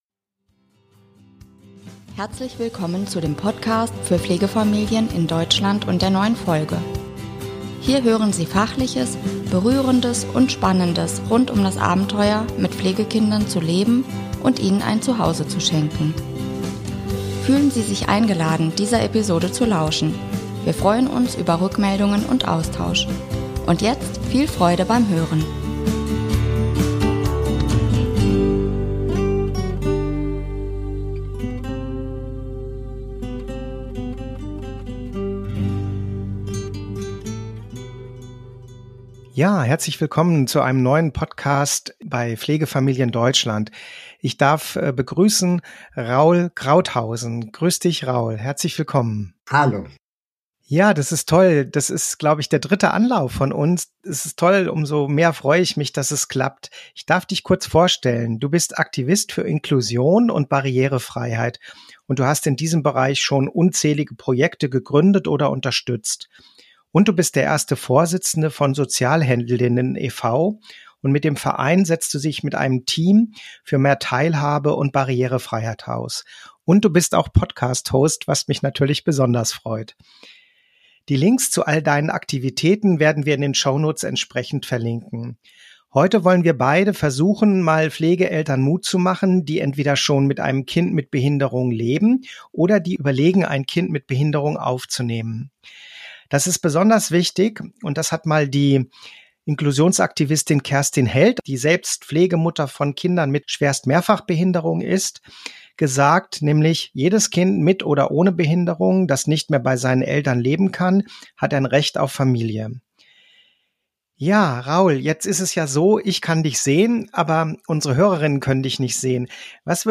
Interview mit: Raul Krauthausen